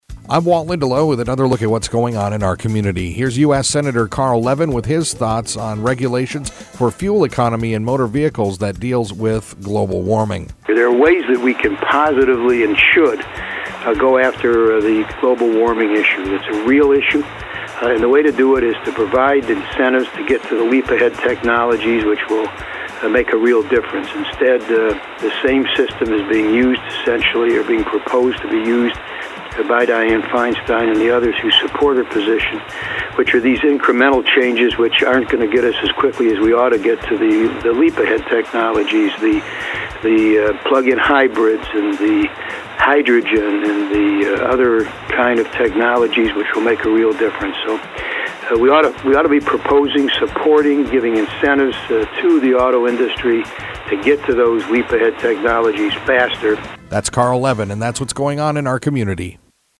INTERVIEW: US Senator Carl Levin